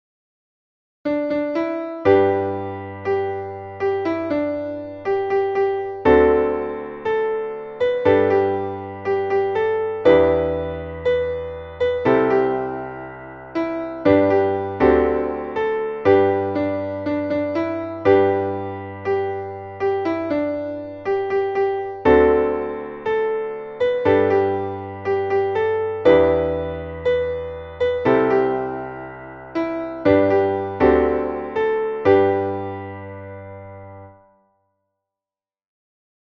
Traditional / Spiritual / Gospel